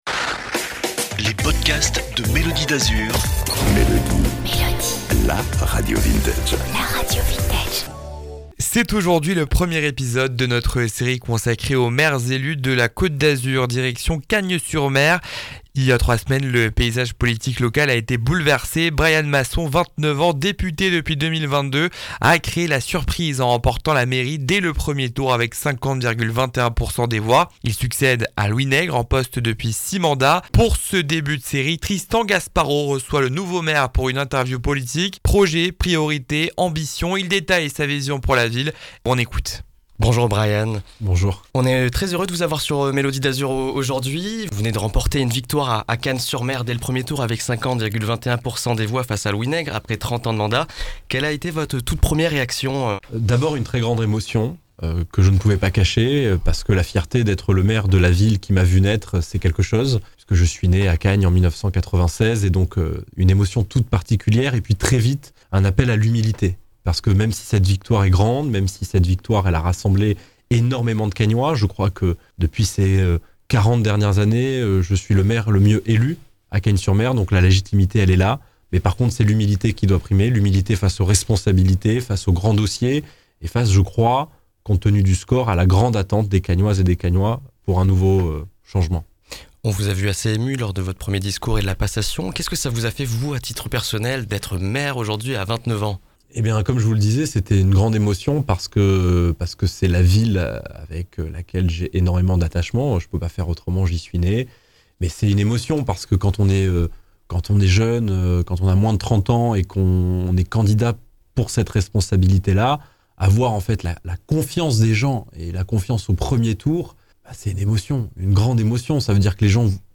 reçoit le nouveau maire pour une interview politique.